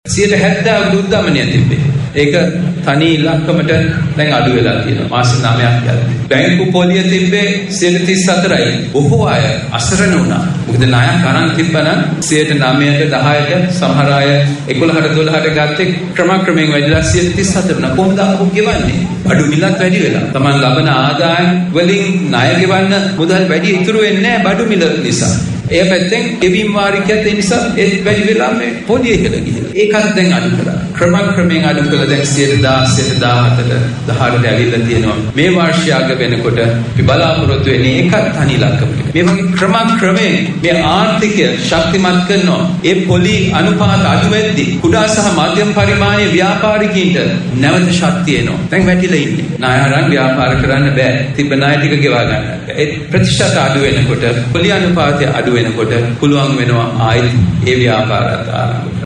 සිරිකොත පක්ෂ මූලස්ථානයේ දී පැවති බල මණ්ඩල රැස්වීමක් අමතමින් ජනාධිපති ජේ්‍යෂ්ඨ උපදේශක සහ කාර්ය මණ්ඩල ප්‍රධානී සාගල රත්නායක මහතා මේ බව සඳහන් කළා.